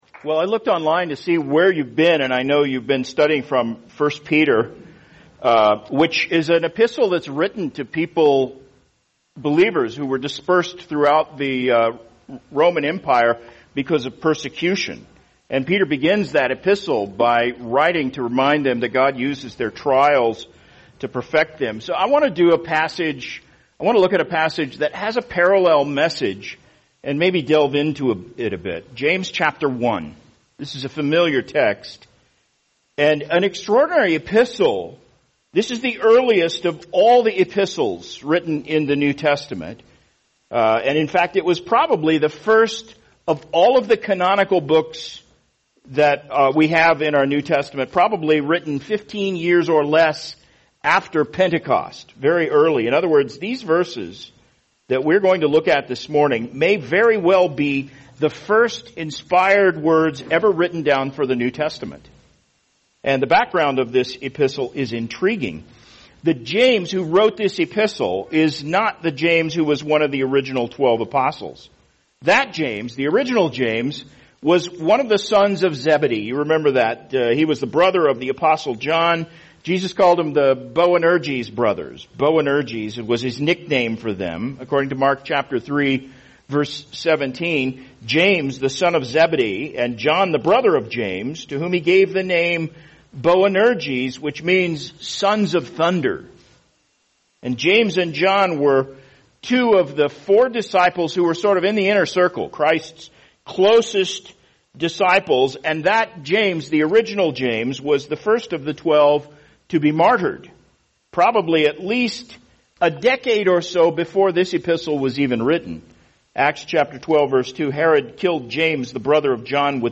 [sermon] James 1:1-4 Finding Joy in Life’s Trials | Cornerstone Church - Jackson Hole